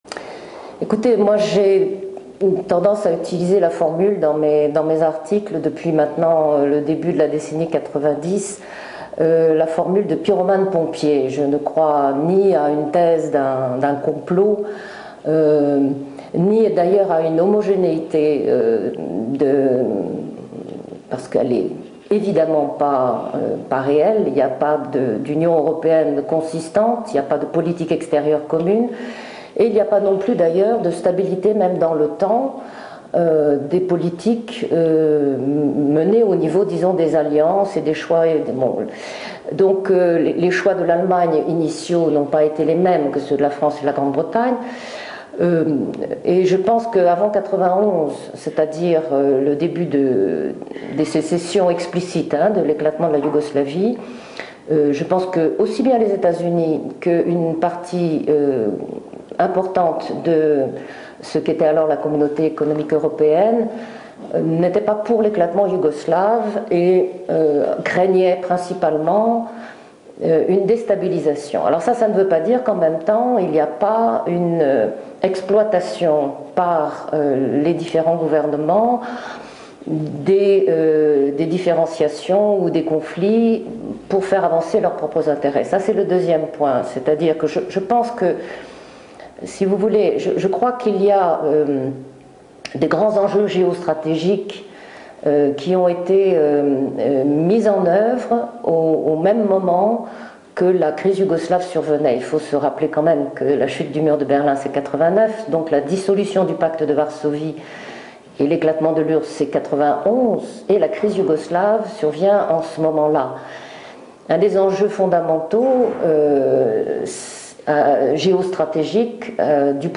La vidéo originale de l’IRIS (Institut de Relations Internationales et Stratégiques) a été convertie en deux fichiers MP3 distincts car cette dernière était d’une piètre qualité (image dégradée accompagnée d’un décalage permanent entre l’élocution et la bande sonore).